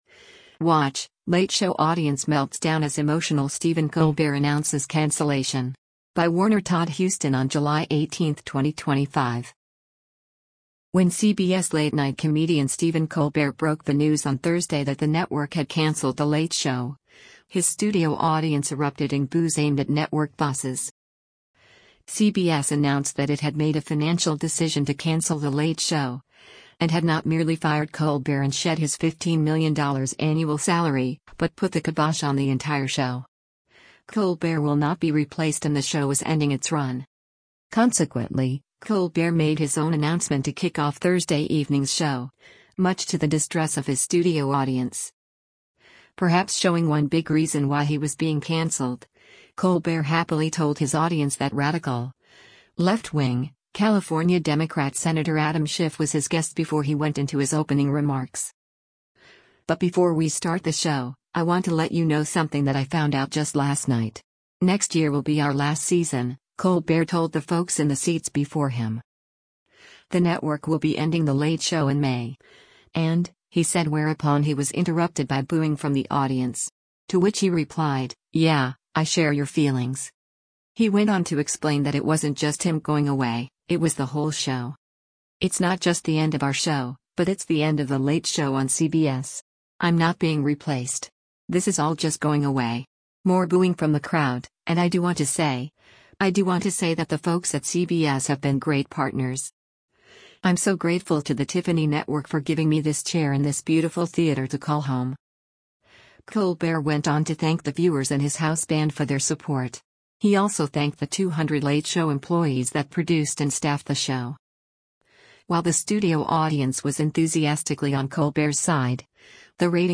Watch: ‘Late Show’ Audience Melts Down as Emotional Stephen Colbert Announces Cancellation
When CBS late-night comedian Stephen Colbert broke the news on Thursday that the network had canceled The Late Show, his studio audience erupted in “boos” aimed at network bosses.
“The network will be ending The Late Show in May, and…” he said whereupon he was interrupted by booing from the audience.